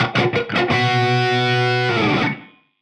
Index of /musicradar/80s-heat-samples/85bpm
AM_HeroGuitar_85-B02.wav